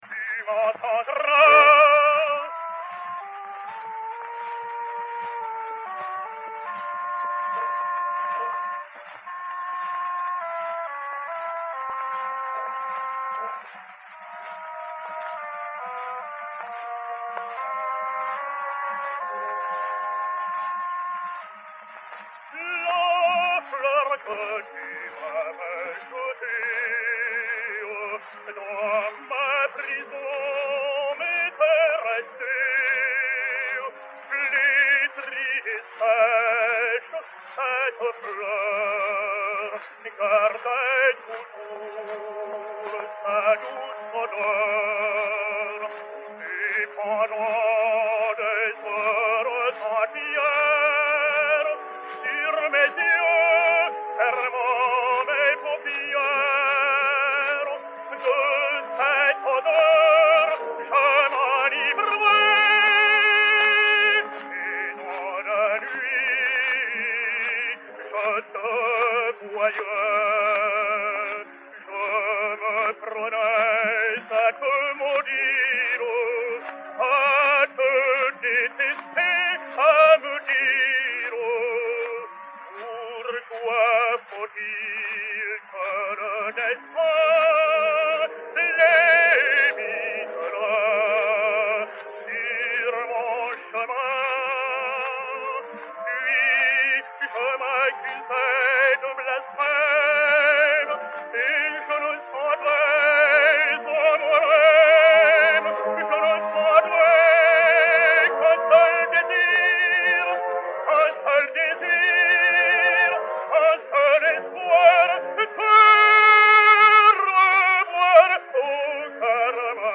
Agustarello Affre (Don José) et Orchestre
Pathé saphir 80 tours n° 177, mat. 3486, enr. à Paris vers 1910